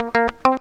GTR 93 CM.wav